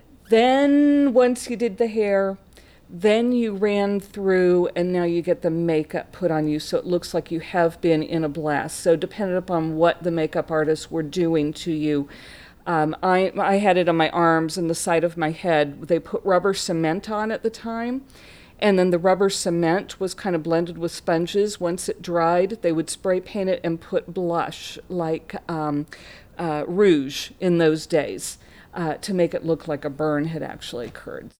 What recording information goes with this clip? The interview was conducted at the Watkins Museum of History on June 28, 2012.